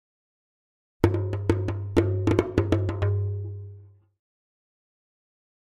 Drums Short African Dance 1